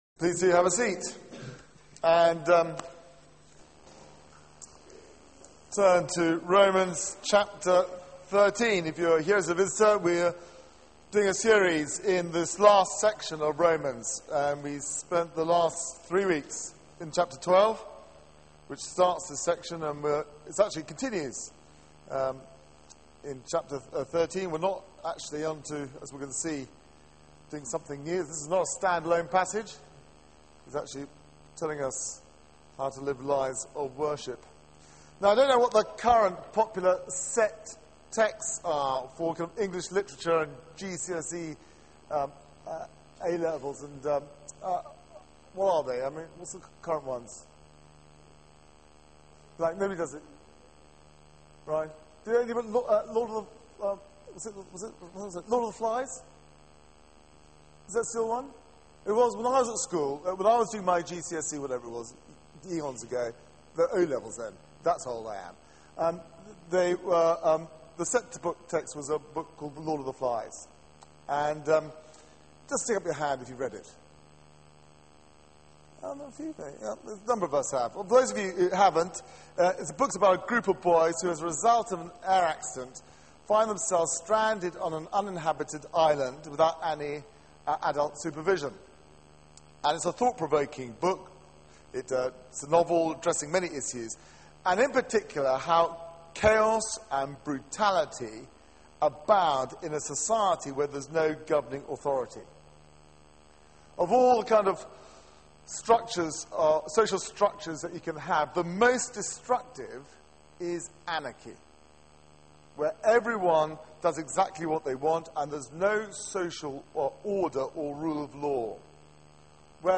Media for 6:30pm Service on Sun 23rd Sep 2012 18:30 Speaker
Series: The Christian Life Theme: The Christian and society Sermon